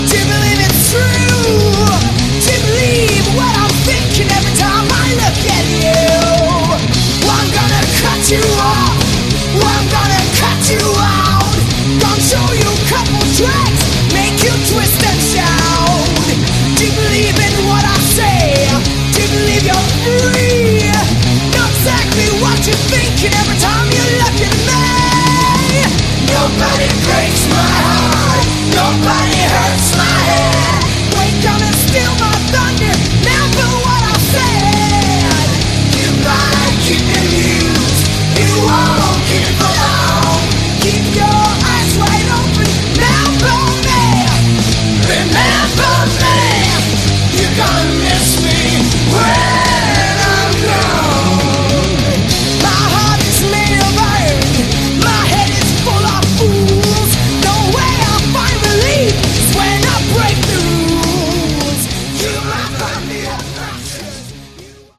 Category: Modern Melodic Metal
guitars
bass
drums